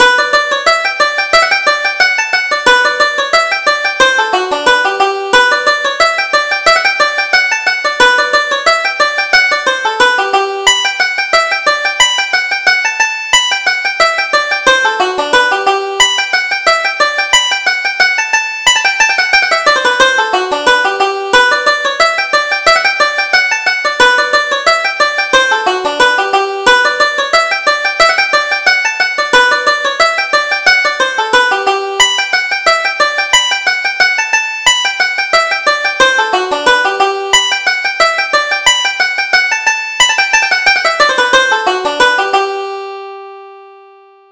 Reel: From Shore to Shore